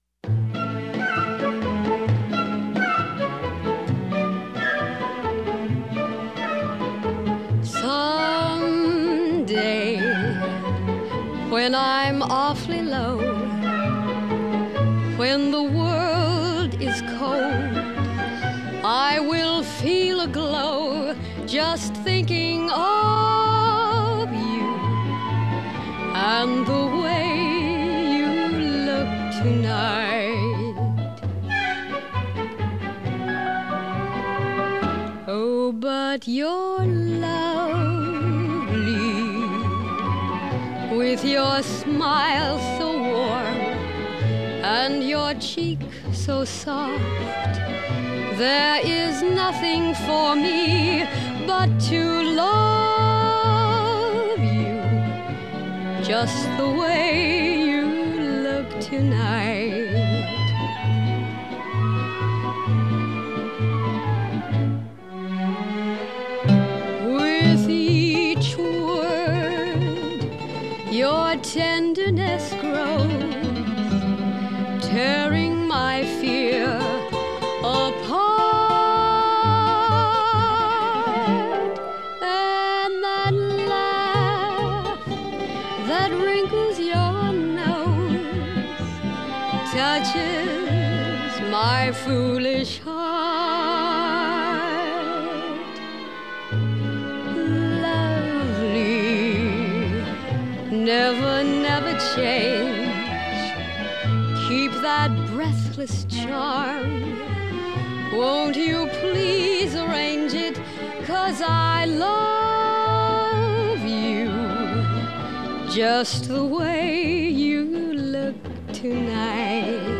1936   Genre: Soundtrack   Artist